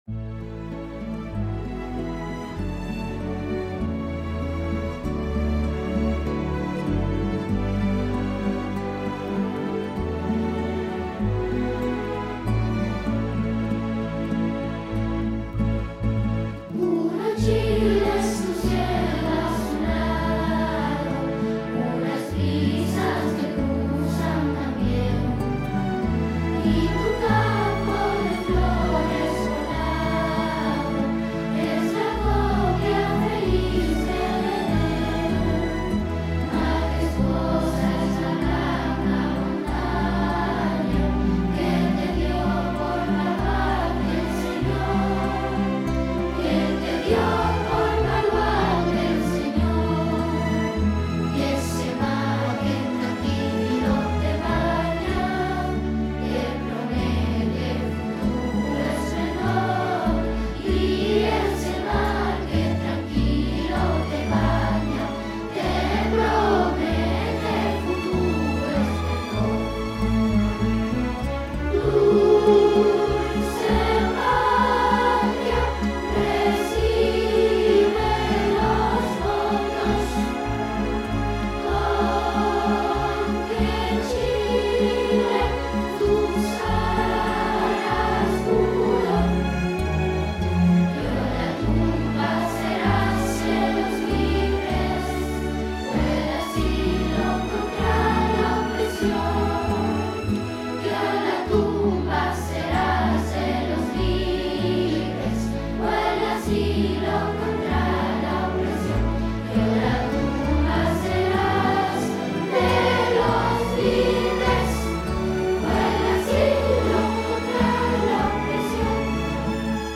Himno nacional de Chile – Coro R. de Siria sede Básica 2025